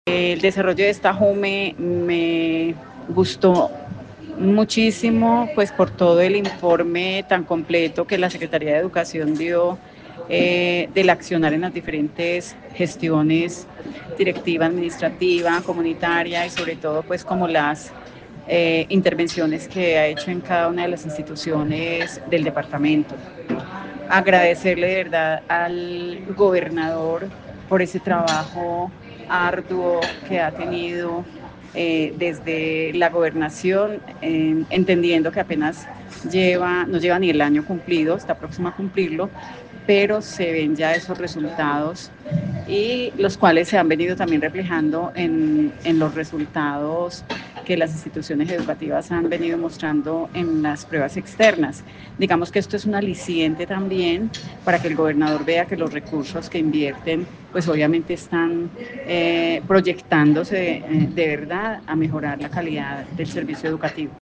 En la reciente Junta Departamental de Educación (JUDE) 2024 se presentó un balance de los logros alcanzados en el sistema educativo de Risaralda, con énfasis en la mejora de la calidad educativa, el bienestar docente y la cobertura.